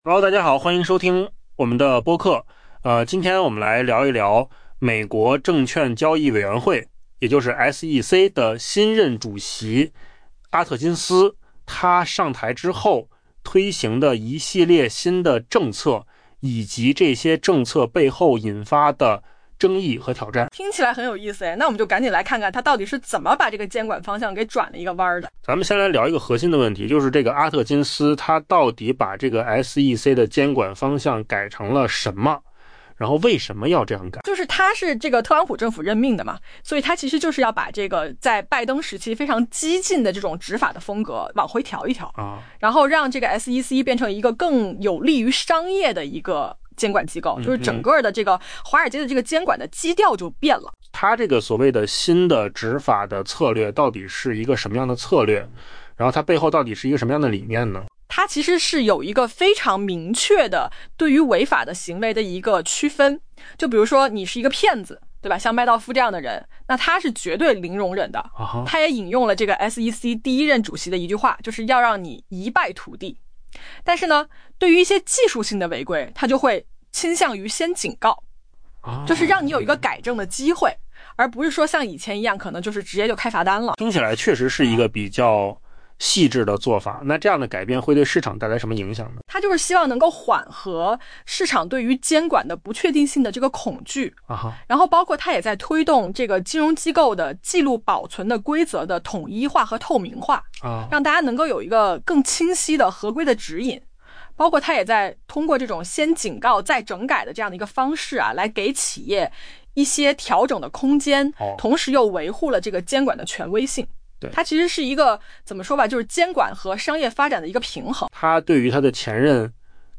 AI 播客：换个方式听新闻 下载 mp3 音频由扣子空间生成 美国最高华尔街监管机构负责人正着手废除前总统拜登时期的激进执法议程，承诺在 「破门而入」 前就技术性违规向企业发出警告。